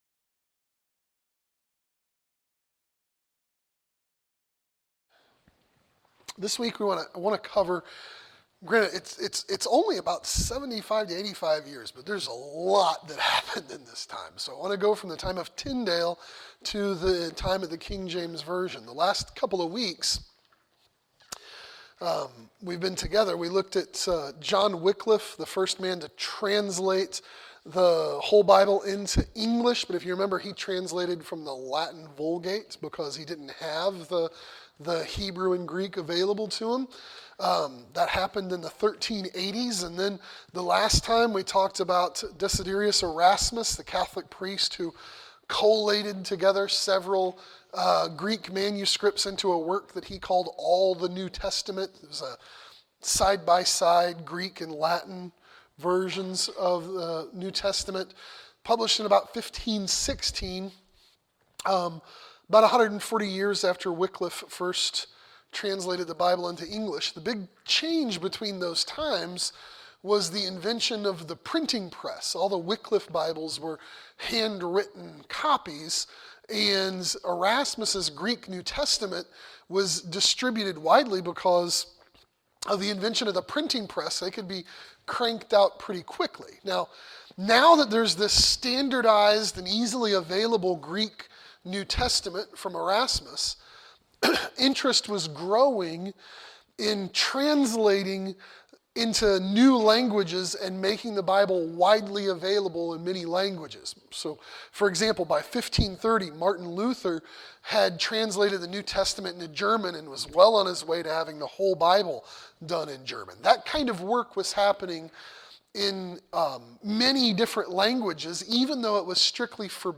From Tyndale to the KJV | SermonAudio Broadcaster is Live View the Live Stream Share this sermon Disabled by adblocker Copy URL Copied!